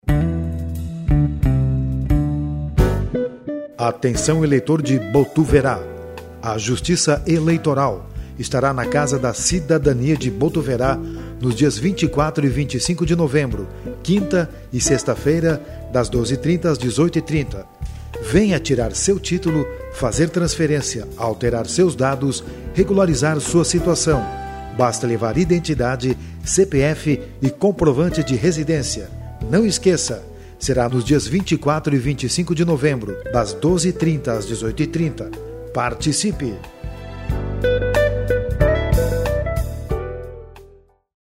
spot_botuvera.mp3